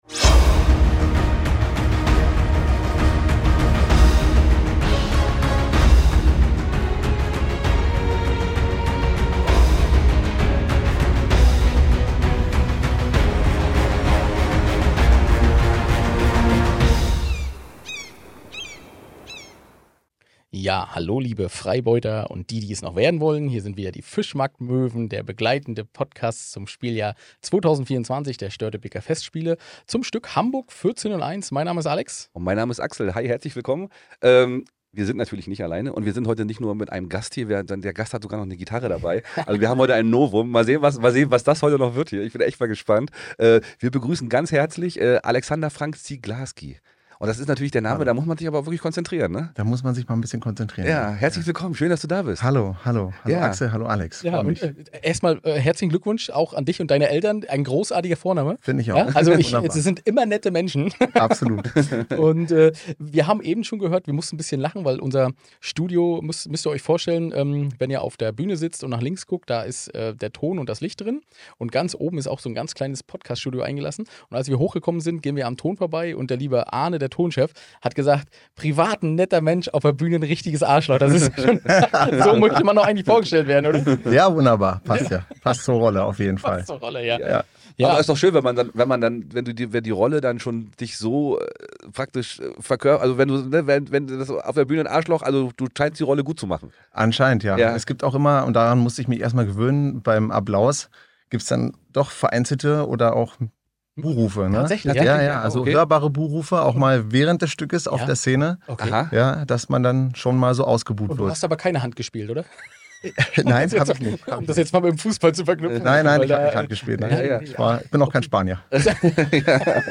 sympathischen Art war es ein leichtes dieses Interview zu machen.